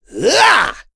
Kasel-Vox_Attack3.wav